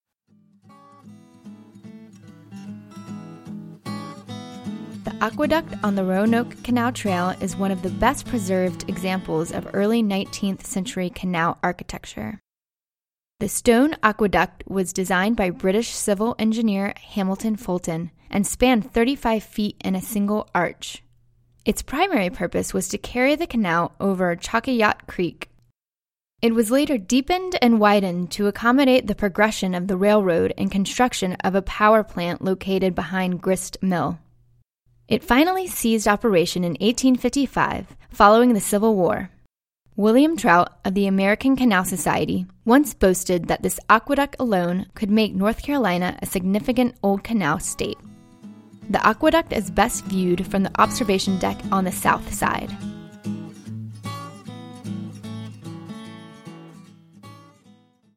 Aqueduct - Audio Tour